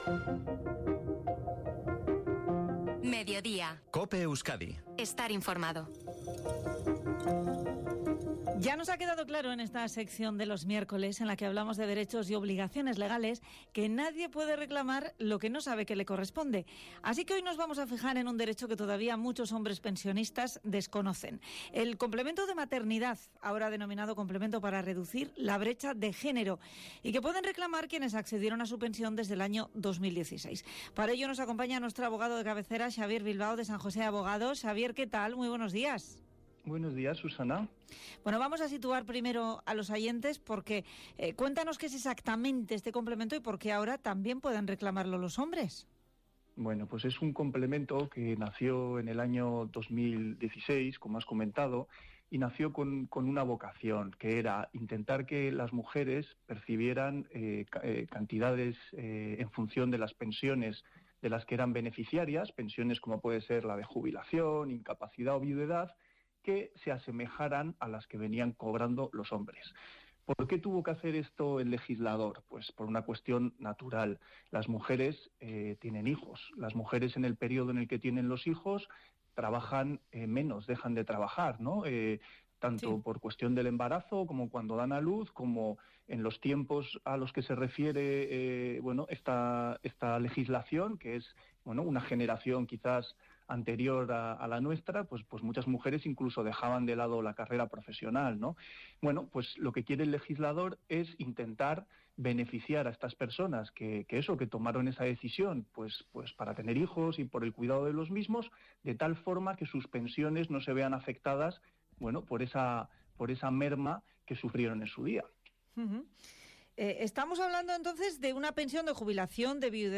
ENTREVISTA EN COPE SOBRE EL COMPLEMENTO DE MATERNIDAD O REDUCCION DE BRECHA DE GENERO PARA HOMBRES - Despacho Abogados San Jose
ENTREVISTA-4-MARZO.mp3